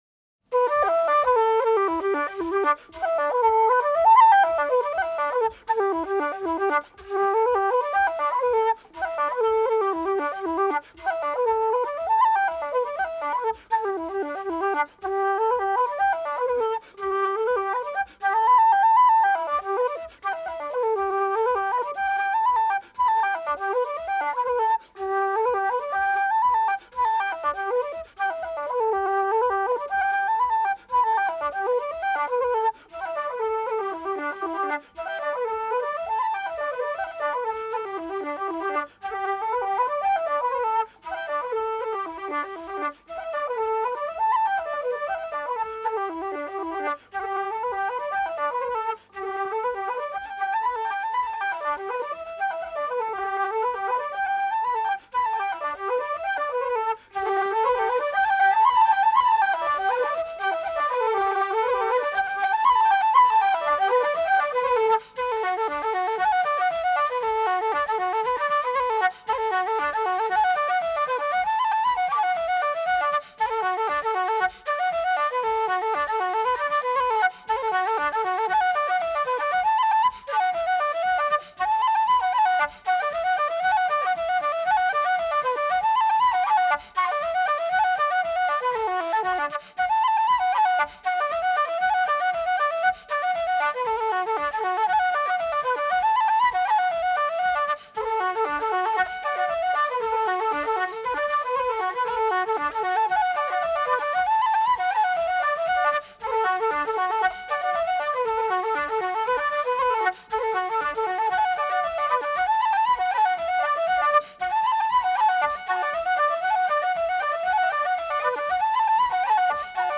The first reel is Midsummer’s Night.